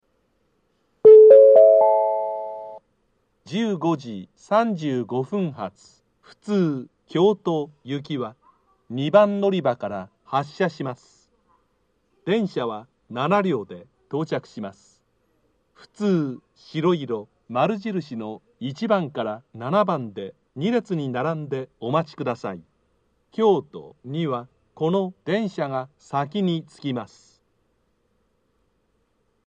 （男性）
到着予告放送 15：35発 普通 京都行き 7両編成の自動放送です。